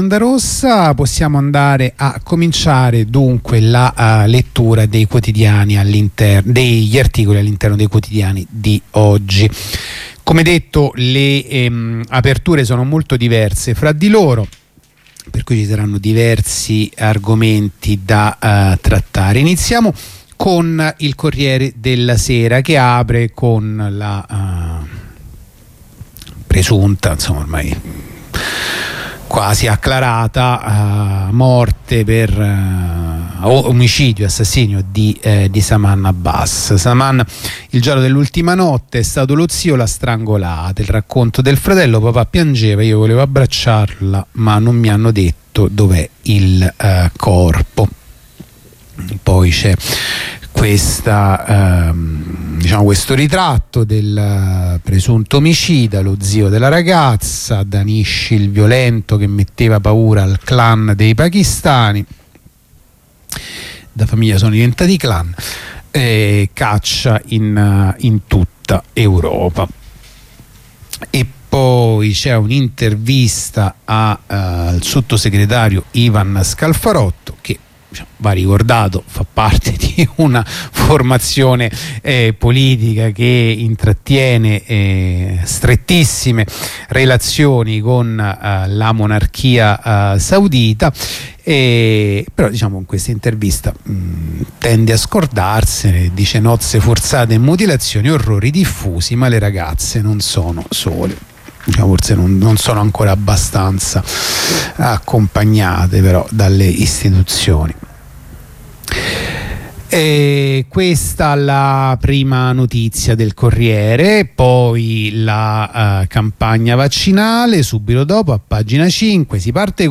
La rassegna stampa di martedì 8 giugno 2021
La rassegna stampa di radio onda rossa andata in onda martedì 8 giugno 2021